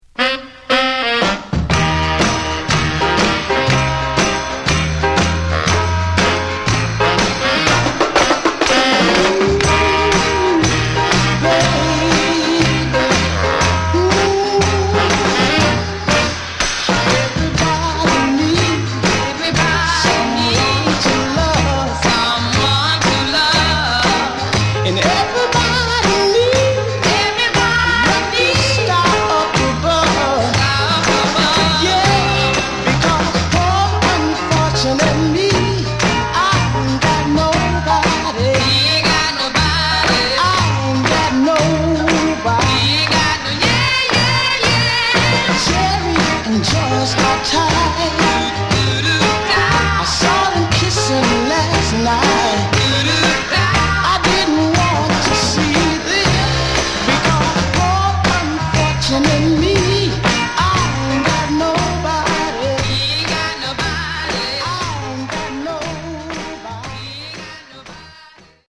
Genre: Northern Soul, Motown Style
is a non-stop crazy Northern Soul dancer.
This is the true Motown sound